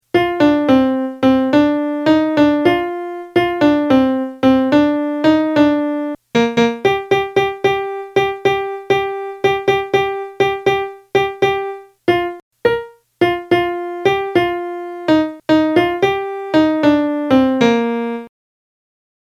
giulietta-melody.mp3